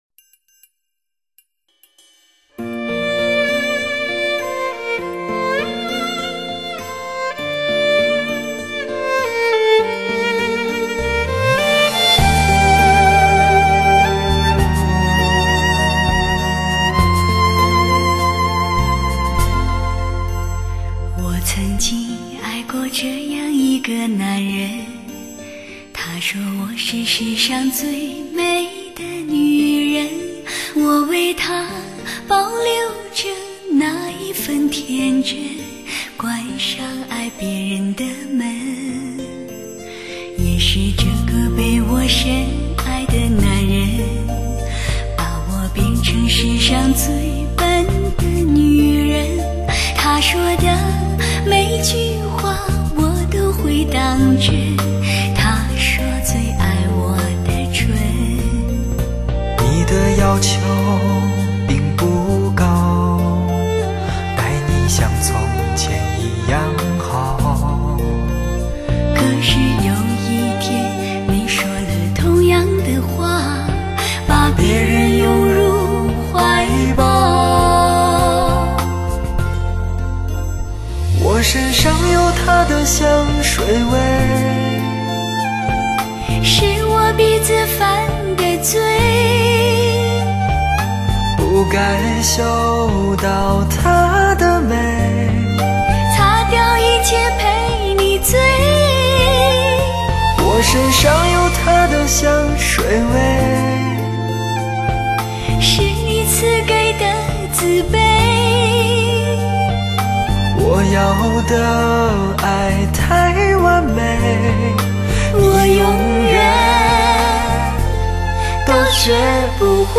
绝世情歌对唱